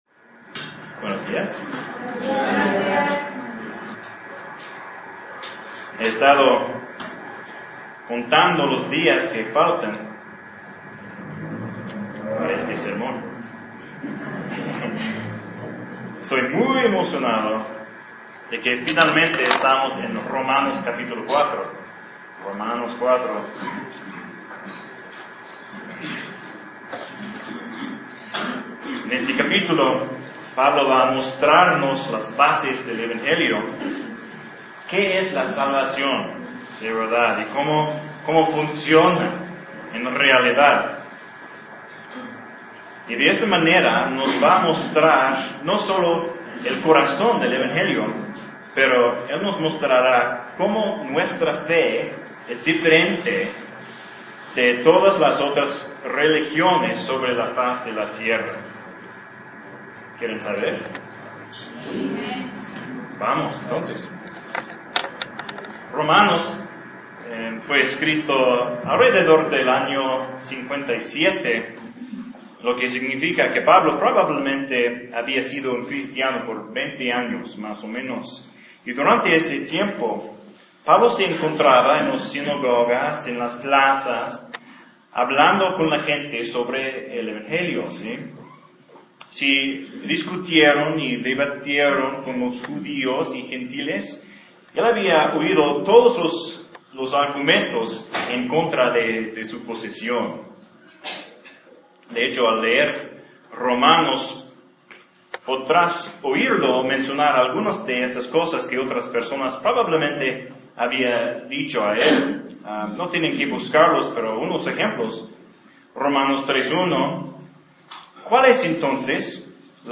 Esta es la primera parte de un sermón de dos partes acerca de Romanos capítulo 4.